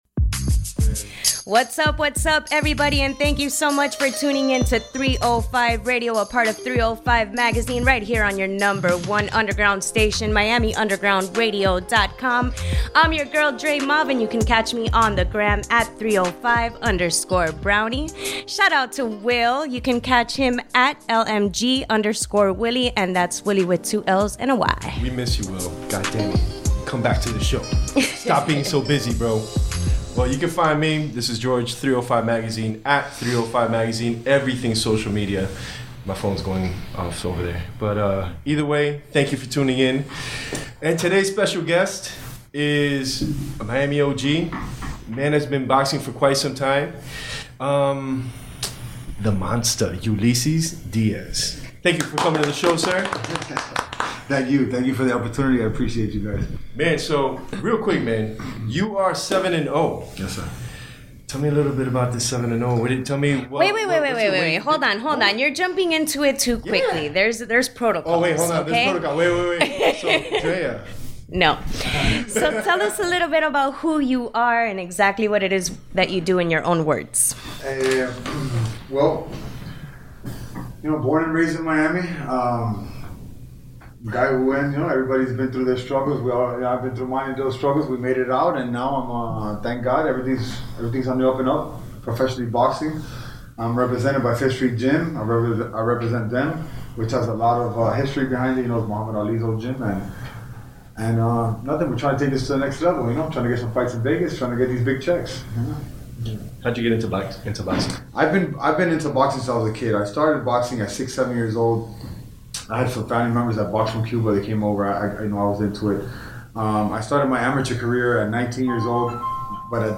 in the studio!